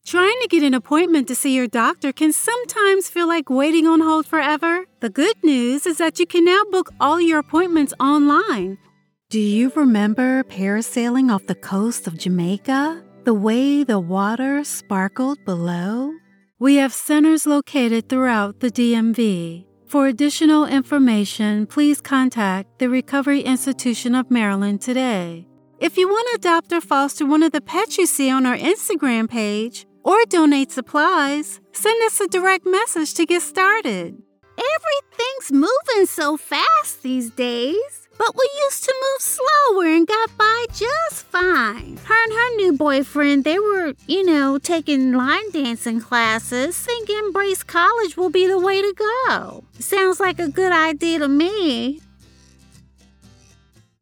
I strive to make your project stand out by making characters come to life through powerful voice over work.
VOICE OVER TALENT
Fiverr-Demo-Commercial.Final_.mp3